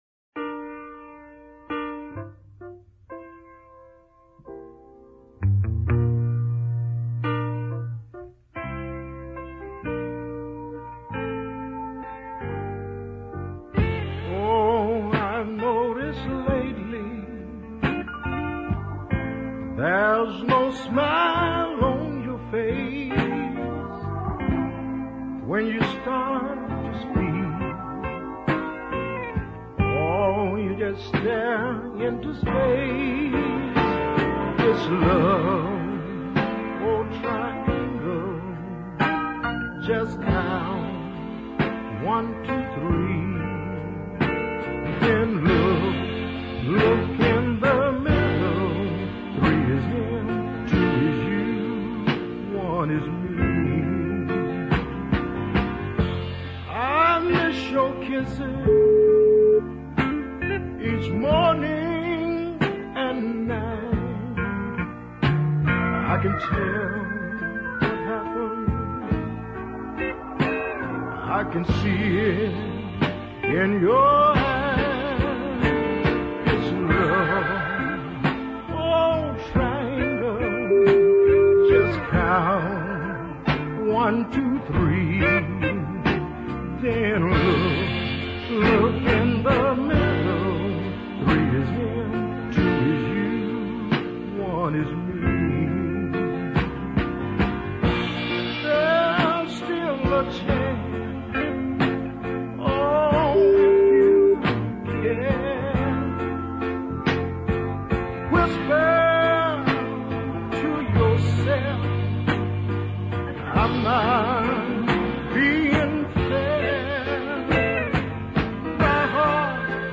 delicate country ballad